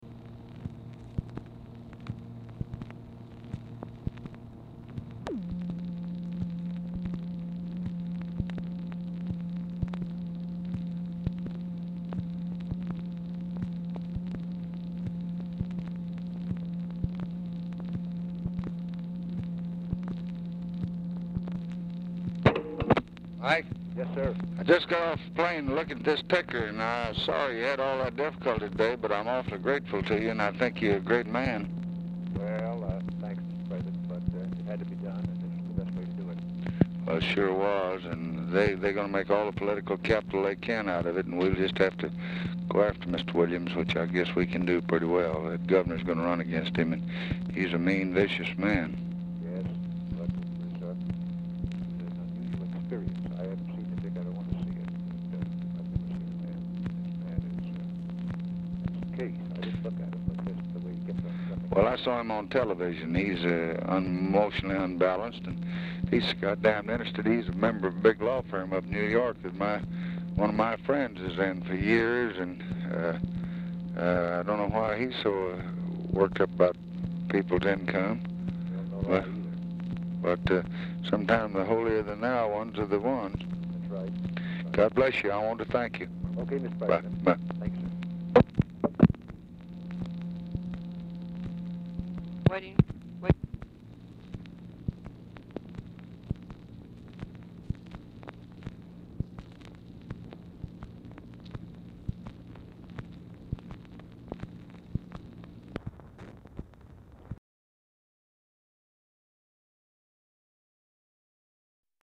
Telephone conversation # 3457, sound recording, LBJ and MIKE MANSFIELD, 5/14/1964, 8:38PM
MANSFIELD DIFFICULT TO HEAR
Format Dictation belt